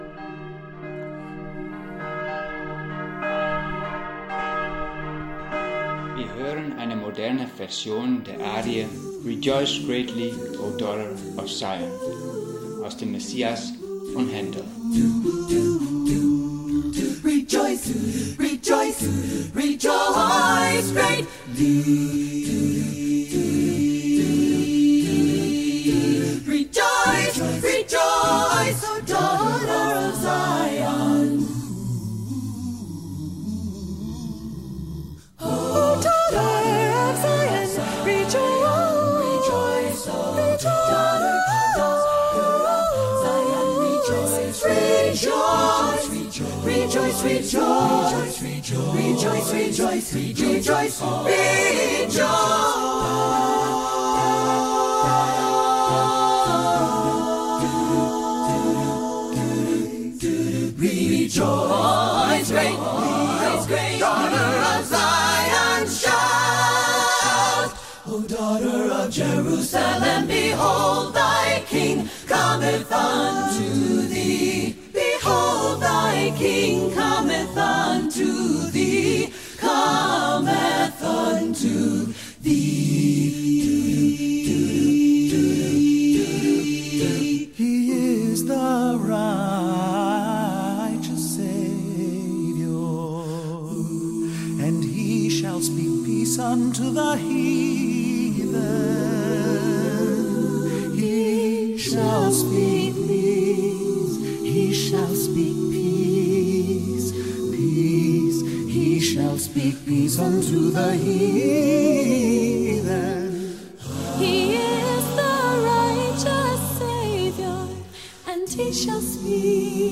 Meditationspodcast Sonntag, den 5. Juli 2020